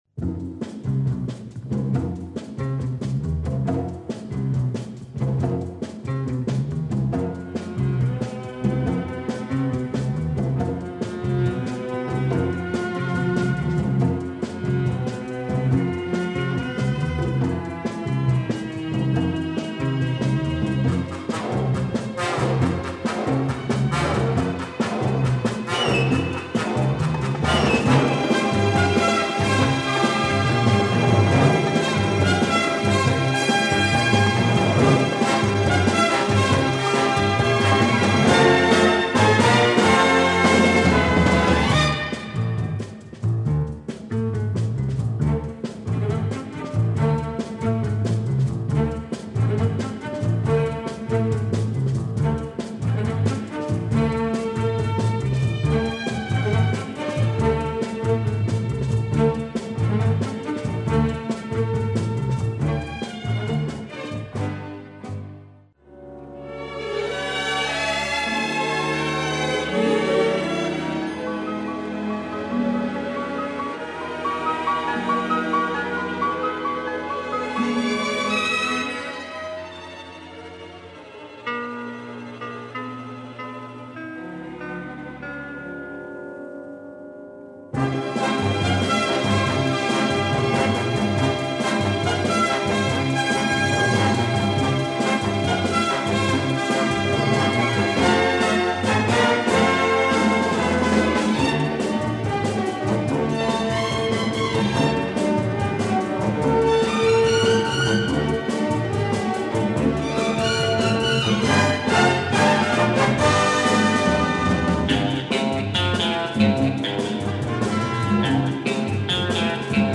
soundtrack SP
sensual lalala female vocals
excellent groovy with drama beats